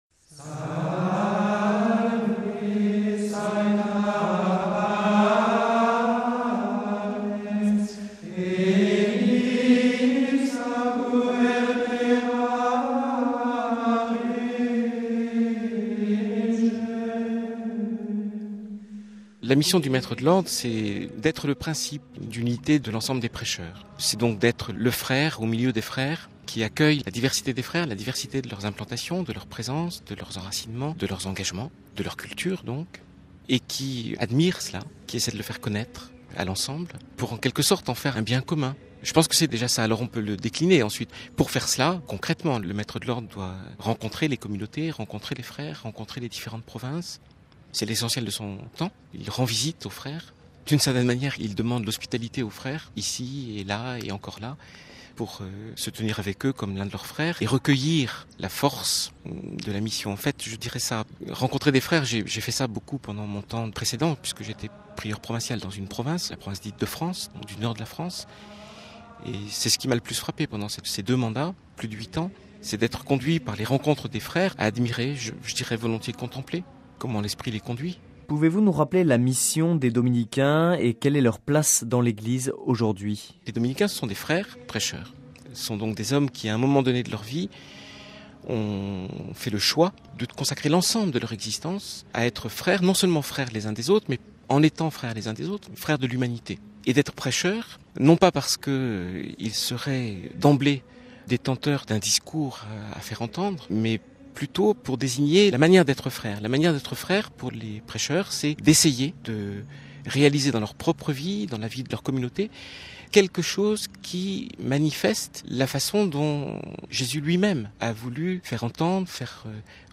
Un entretien
il s'agit d'une rediffusion.